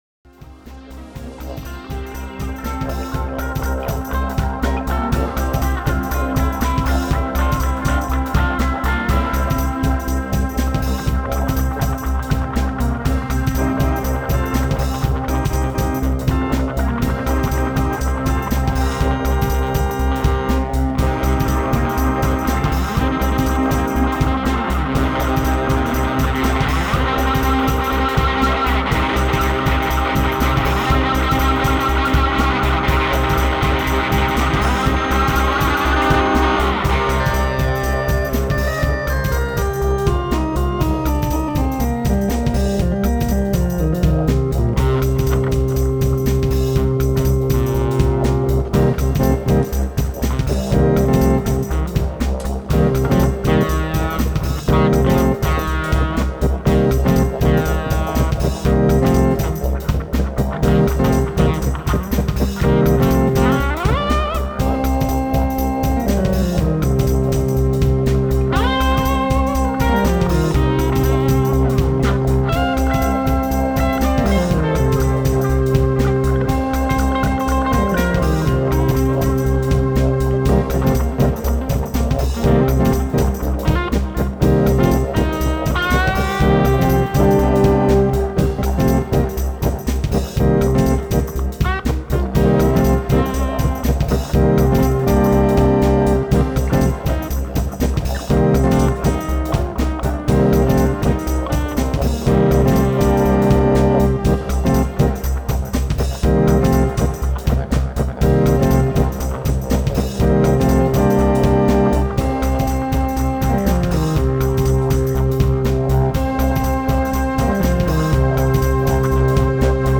(jam)
didgeridoo